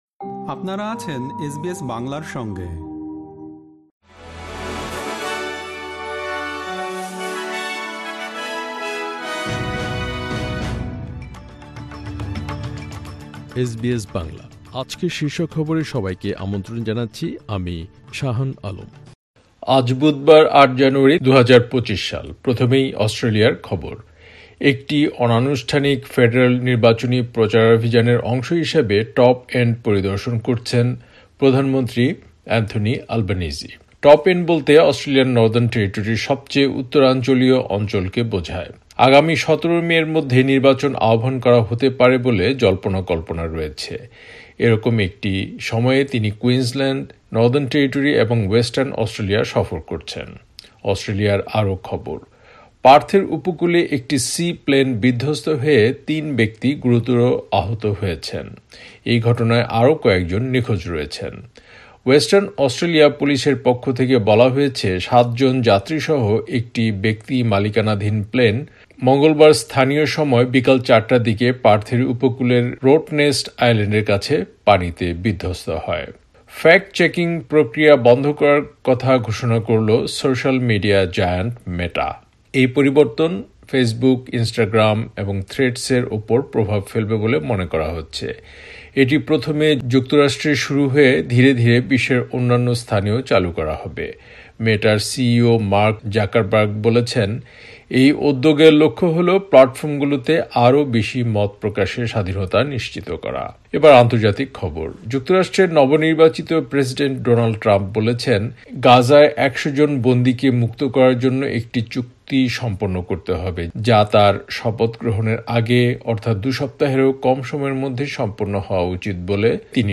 এসবিএস বাংলা শীর্ষ খবর: ৮ জানুয়ারি, ২০২৫